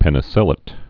(pĕnĭ-sĭlĭt, -āt)